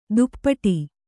♪ duppaṭi